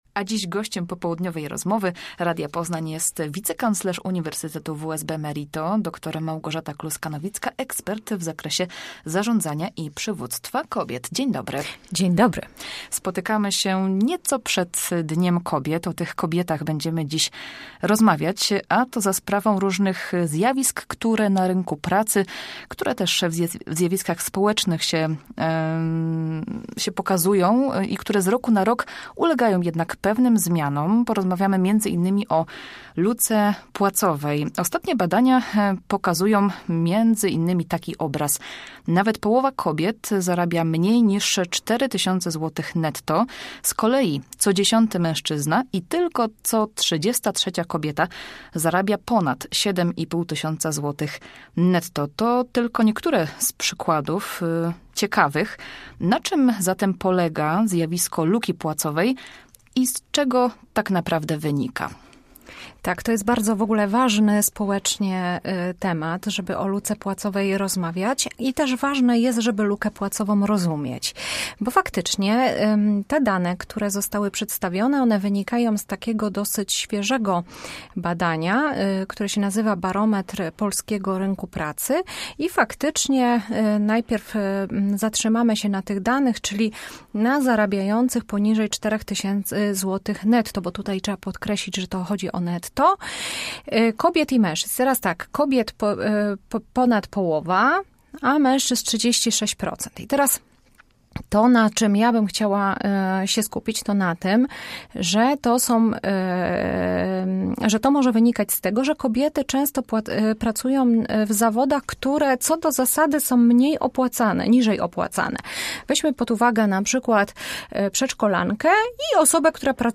Popołudniowa rozmowa Radia Poznań